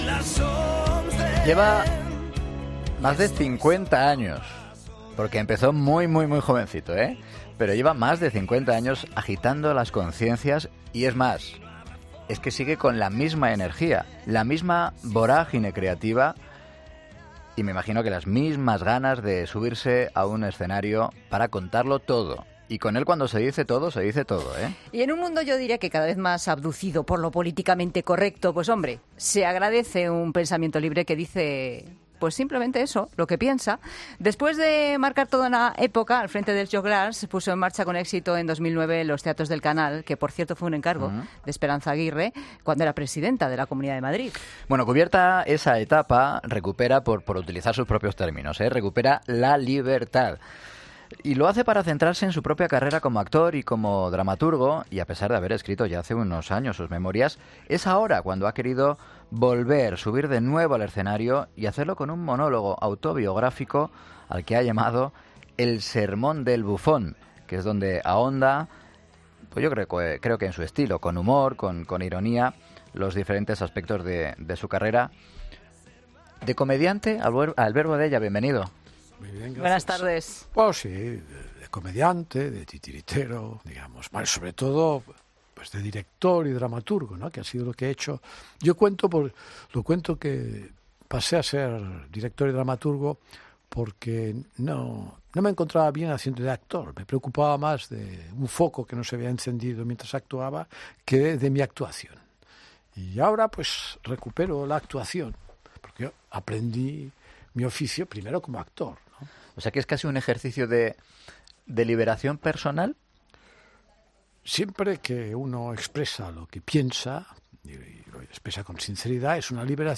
Escucha la entrevista a Albert Boadella en 'Mediodía COPE'